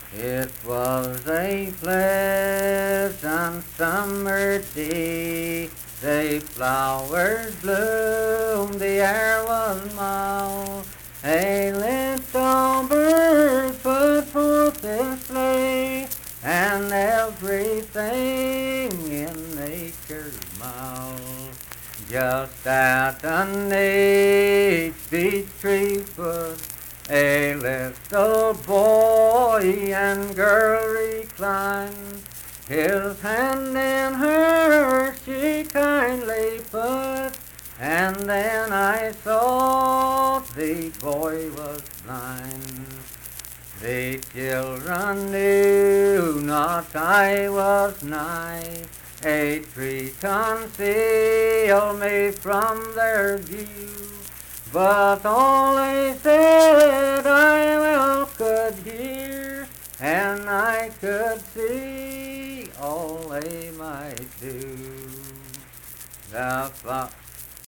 Unaccompanied vocal music performance
Verse-refrain 3(4). Performed in Kliny, Pendleton County, WV.
Voice (sung)